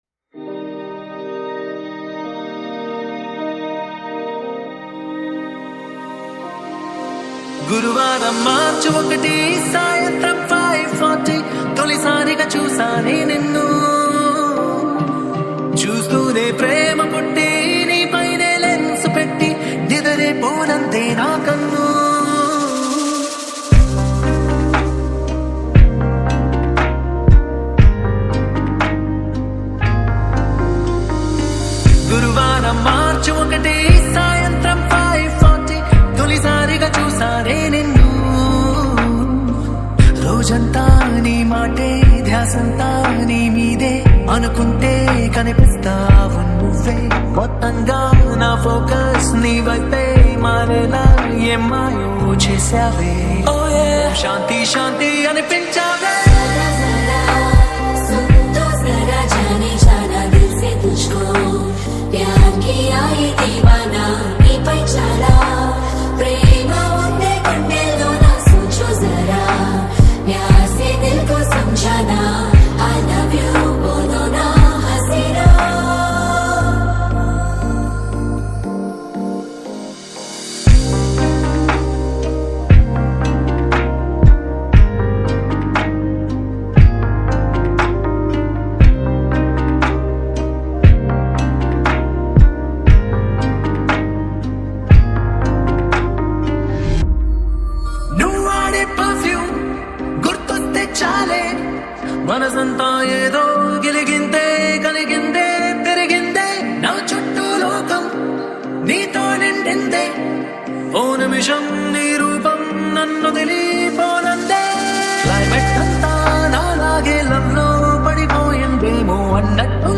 TELUGU LOFI REMIX